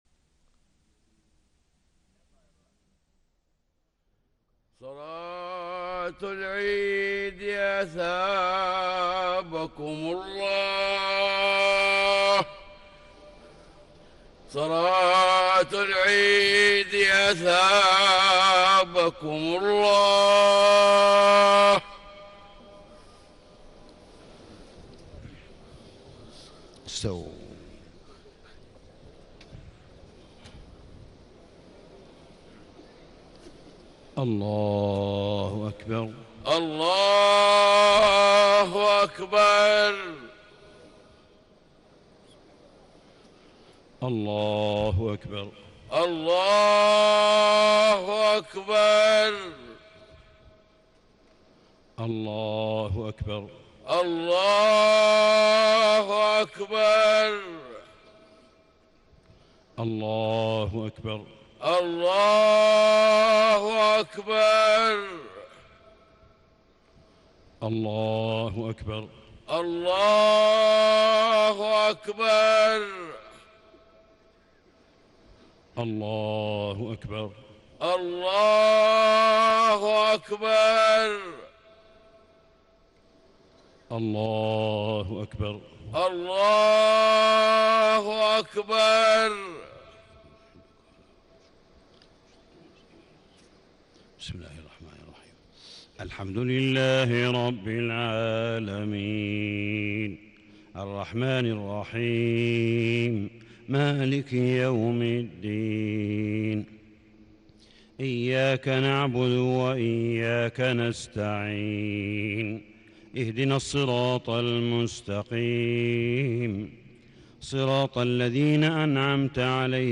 صلاة عيد الفطر المبارك 1 شوال 1437هـ سورتي الأعلى و الغاشية > 1437 🕋 > الفروض - تلاوات الحرمين